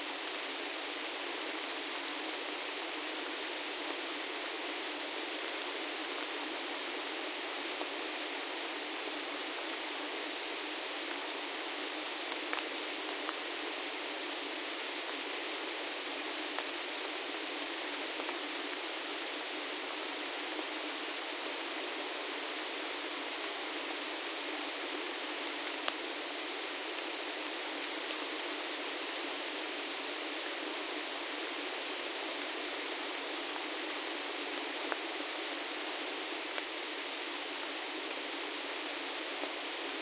sounds_rain_medium.ogg